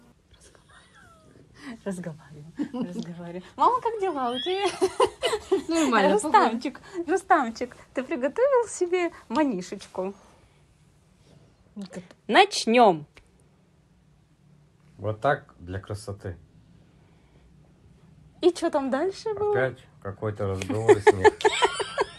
Описание: В поле зрения автора и режиссера типичный и распространенный случай из повседневной жизни - застолье по поводу Дня рождения. Герои фильма присутствуют весело, их действия выражаются через предметы и звуковой ряд.